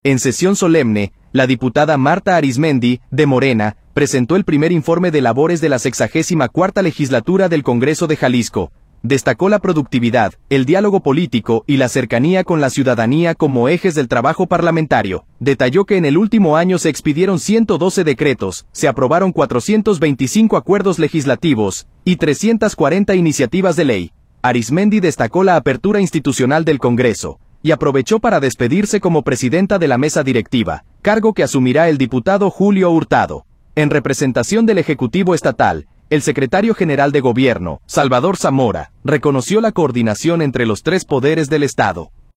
En sesión solemne, la diputada Marta Arizmendi, de Morena, presentó el primer informe de labores de la LXIV Legislatura del Congreso de Jalisco. Destacó la productividad, el diálogo político y la cercanía con la ciudadanía como ejes del trabajo parlamentario. Detalló que en el último año se expidieron 112 decretos, se aprobaron 425 acuerdos legislativos y 340 iniciativas de ley.